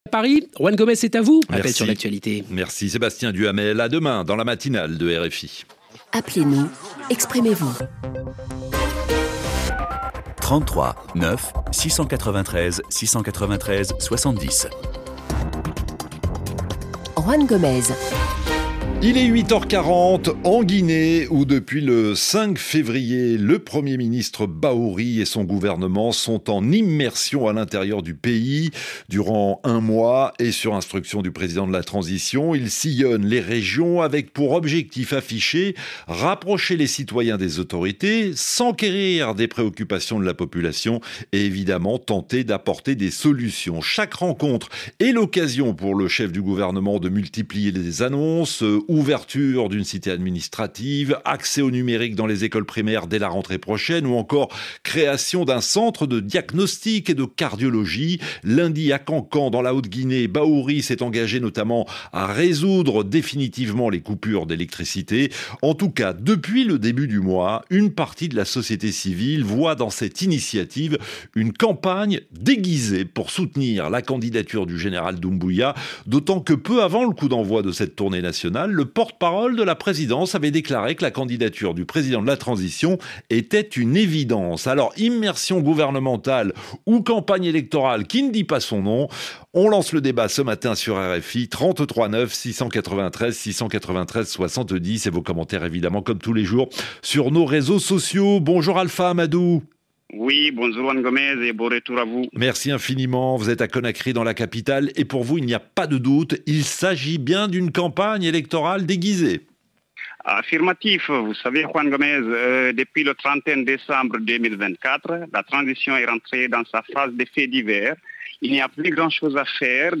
Le rendez-vous interactif des auditeurs de RFI.